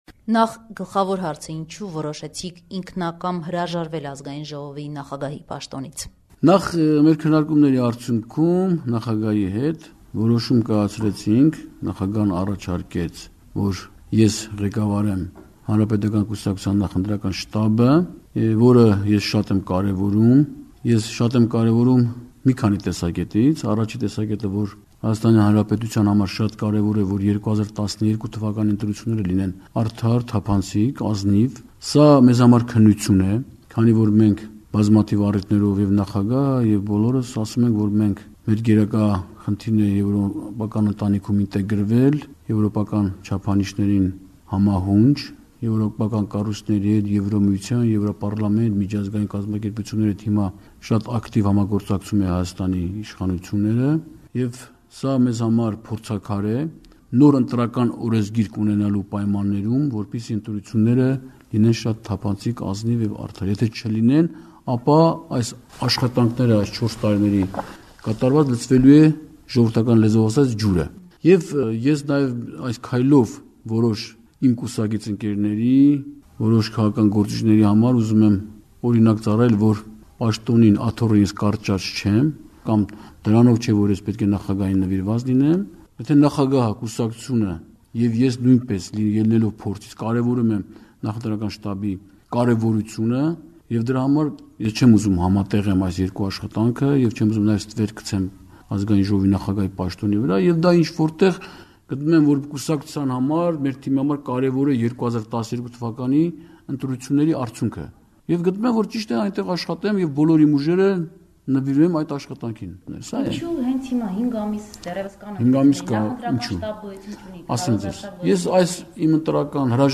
Հարցազրույց․ Ազգային ժողովի նախագահ Հովիկ Աբրահամյանը՝ իր հրաժարականի մասին
«Ազատություն» ռադիոկայանի բացառիկ հարցազրույցը ԱԺ նախագահ Հովիկ Աբրահամյանի հետ։